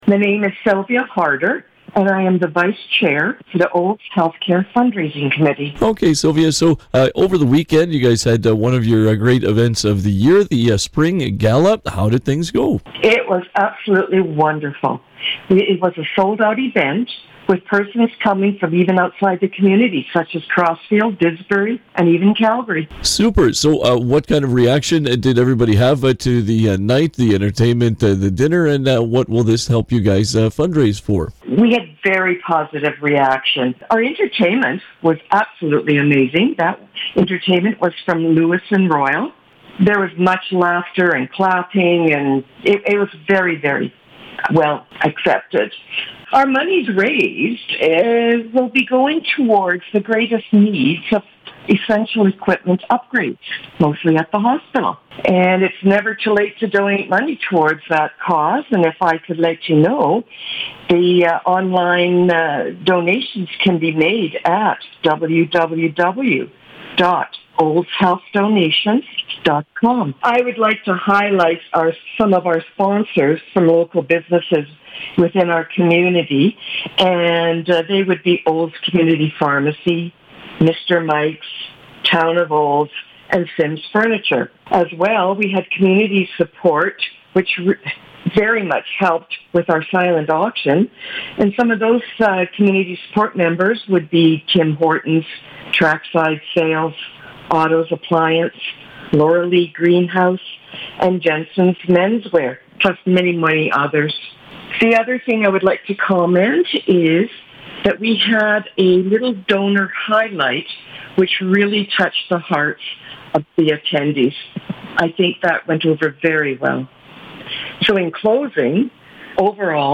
Community Hotline conversation